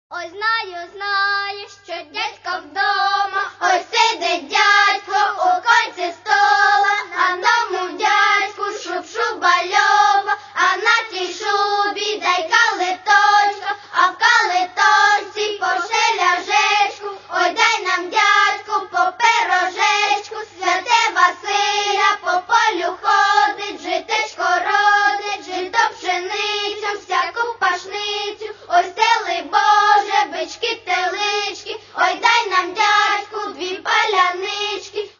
Каталог -> Народна -> Автентичне виконання
Легко. Чисто. Впевнено.
щедрівка